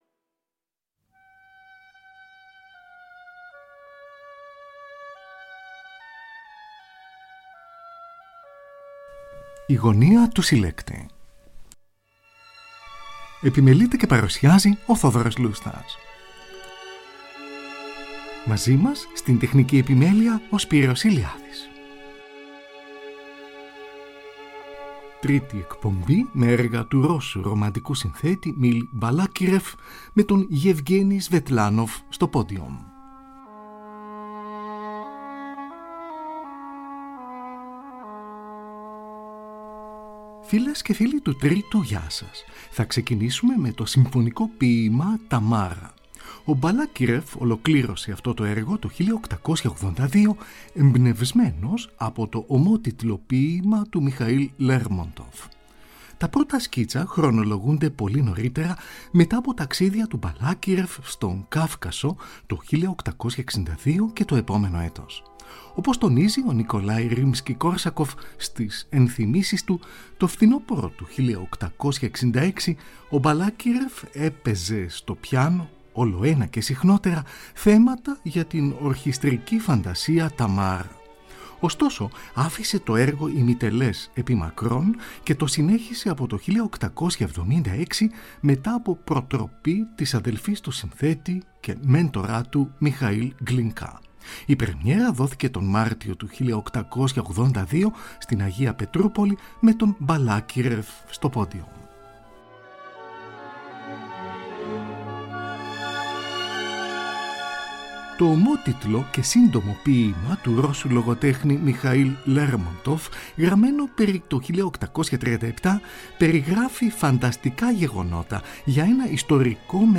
Σουίτα
Συμφωνικό ποίημα